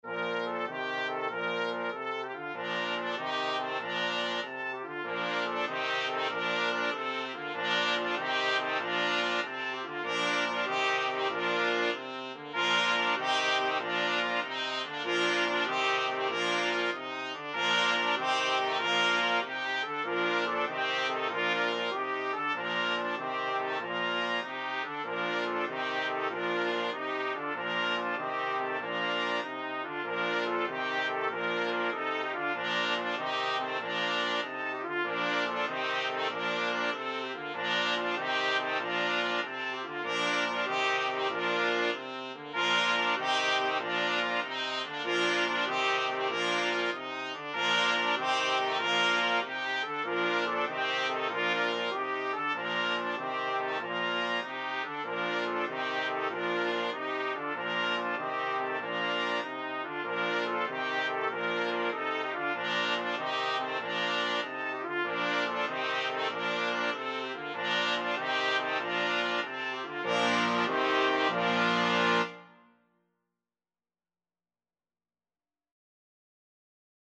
Classical Trad. Sumer is icumen in (Summer is a-coming in) Brass Ensemble version
Trumpet 1Trumpet 2Trumpet 3Trumpet 4Trombone 1Trombone 2
Traditional Music of unknown author.
3/8 (View more 3/8 Music)
Bb major (Sounding Pitch) (View more Bb major Music for Brass Ensemble )
Happily .=c.96
Classical (View more Classical Brass Ensemble Music)